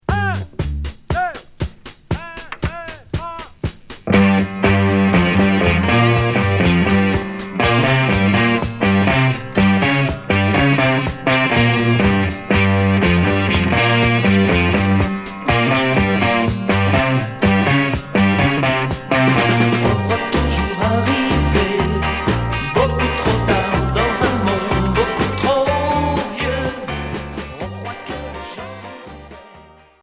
Progressif 45t sorti en Allemagne retour à l'accueil